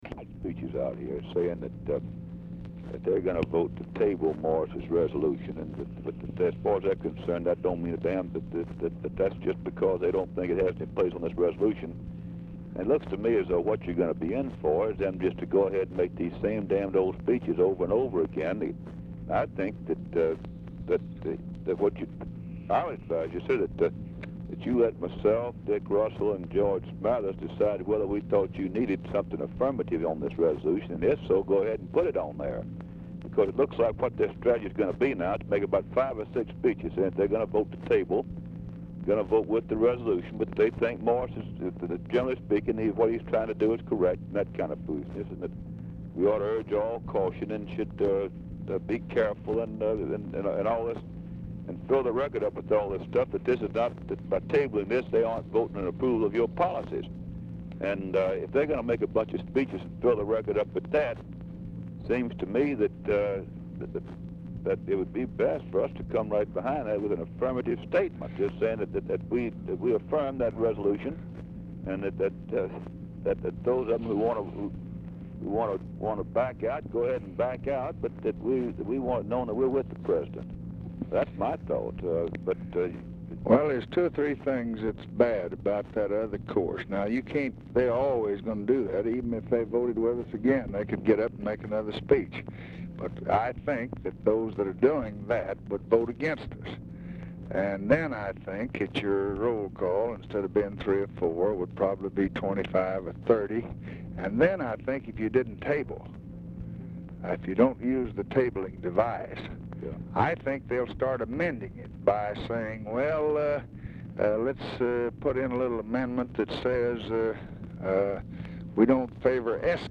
Telephone conversation
RECORDING STARTS AFTER CONVERSATION HAS BEGUN; CONTINUES ON NEXT RECORDING
Format Dictation belt
Location Of Speaker 1 Oval Office or unknown location